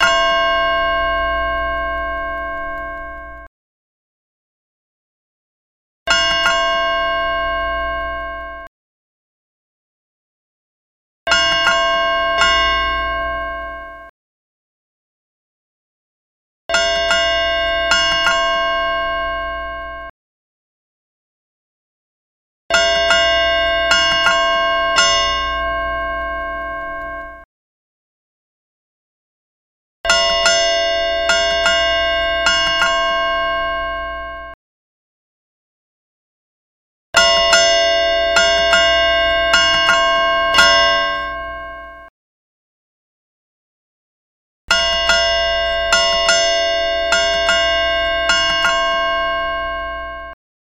Chelsea Ship's Bell Chime
Every half hour adds a bell - reaching eight bells at 4, 8, and 12 o’clock.
🔊 Hear the full sequence
Chelsea’s Ship’s Bell clocks preserve this maritime tradition at home: the mechanism strikes the same authentic sequence you’d hear aboard ship.
chelsea_chime_full_sequence.mp3